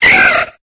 Cri de Rosélia dans Pokémon Épée et Bouclier.